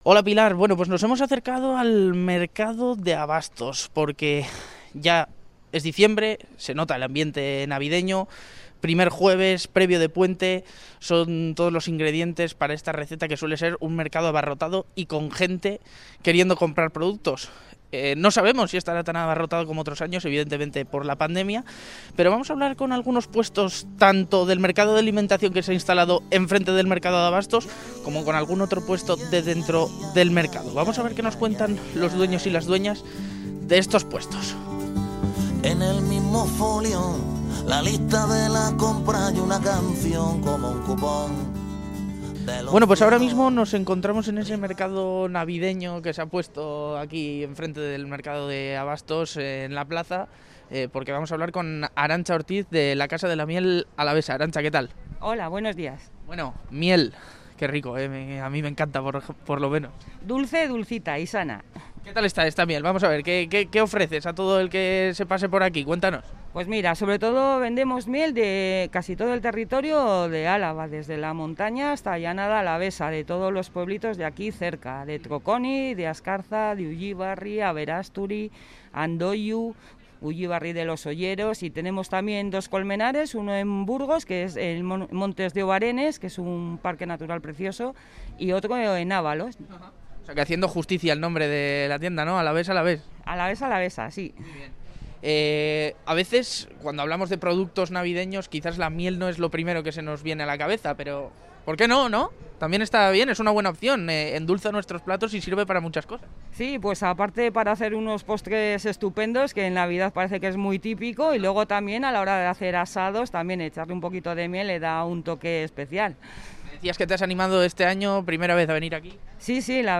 Audio: Es el primer jueves de diciembre, y nos hemos acercado a la Plaza de Abastos para ver cómo están llevando la venta los dueños y las dueñas de los puestos.